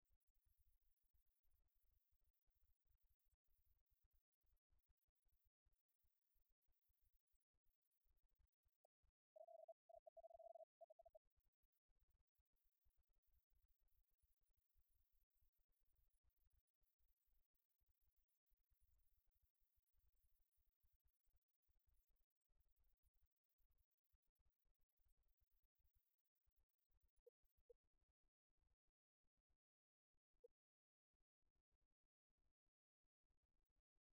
Chorale espagnole
Concert d'un choeur espagnol
Concert d'une chorale espagnole
Pièce musicale inédite